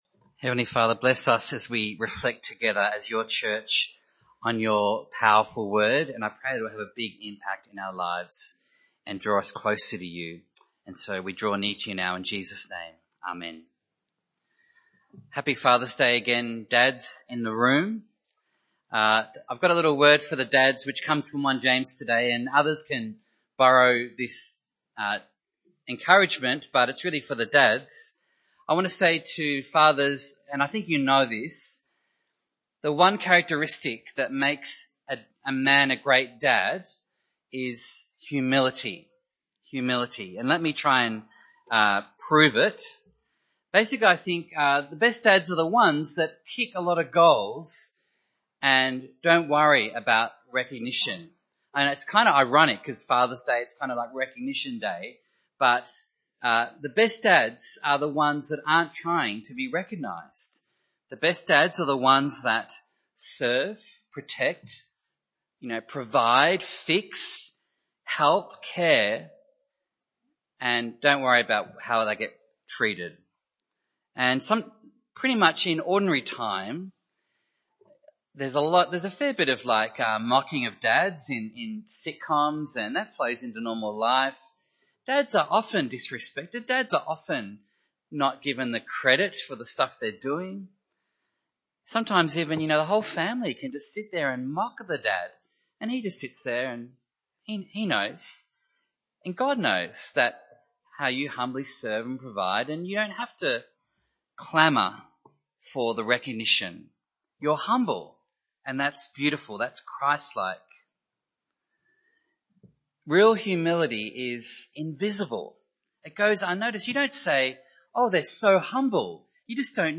Passage: James 4:1-17 Service Type: Sunday Morning